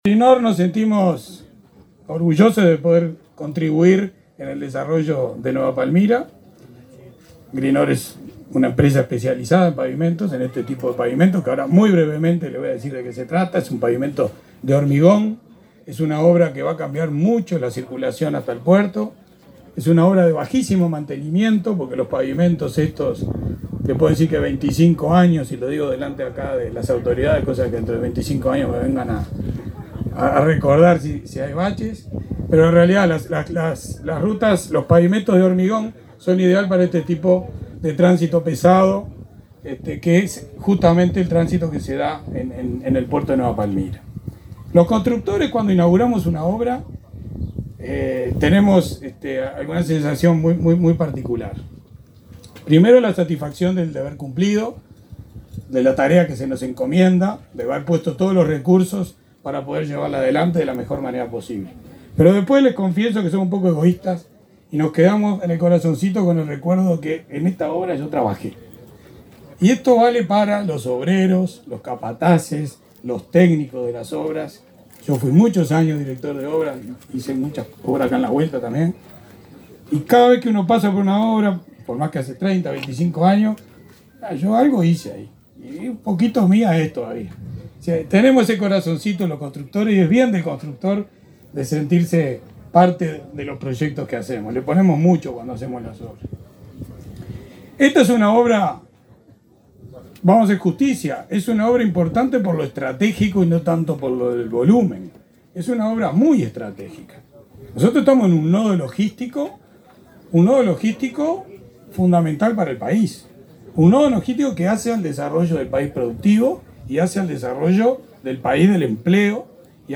el subsecretario de Transporte, Juan José Olaizola; y el intendente de Colonia, Carlos Moreira, fueron los oradores en la inauguración de obras de Nueva Palmira, este sábado 30.